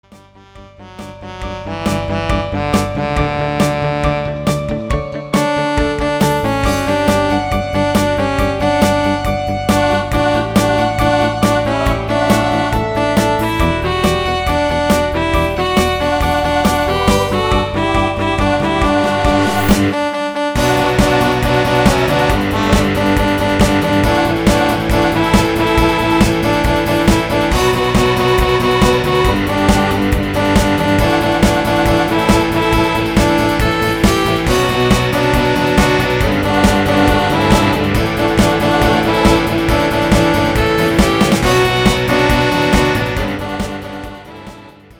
Midi Demo